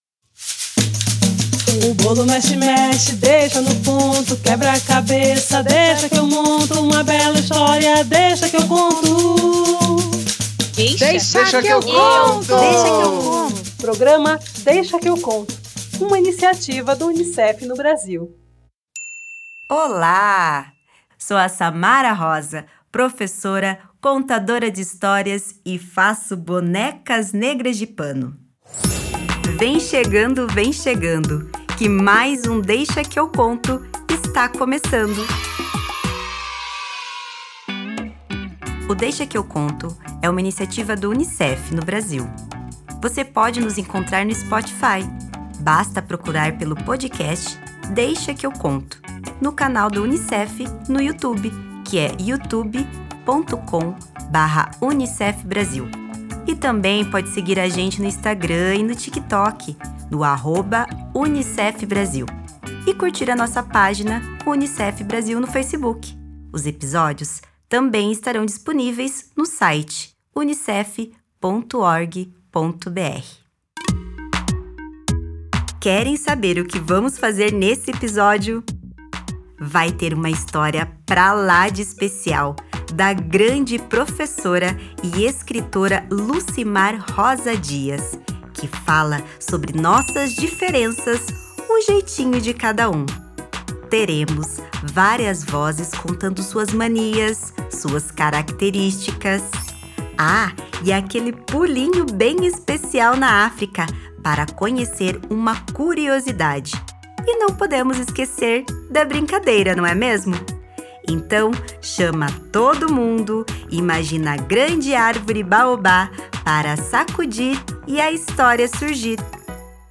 Teremos várias vozes contando sobre suas manias, suas características... Aquele pulinho bem bacana na África para conhecer uma curiosidade e não podemos esquecer a brincadeira, não é mesmo?